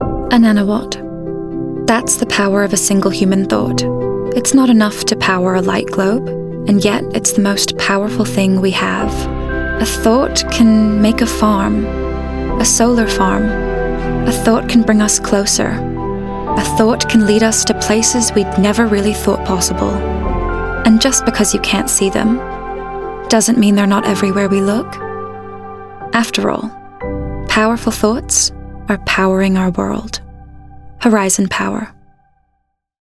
Voice Reel Commercial